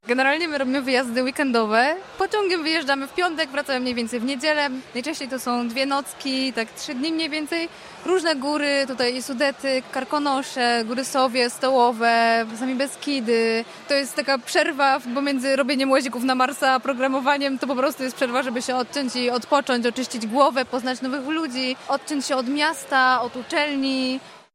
W środę, 29 października, wybraliśmy się na miejsce, żeby dać głos przedstawicielom kół naukowych.